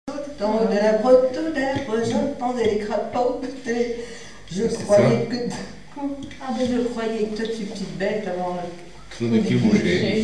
Bois-de-Céné
branle
Couplets à danser
Pièce musicale inédite